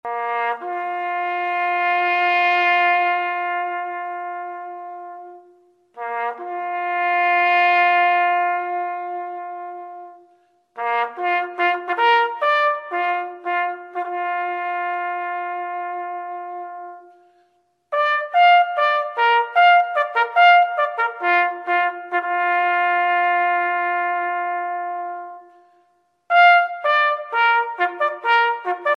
Commonwealth Military Funerals Bugle Calls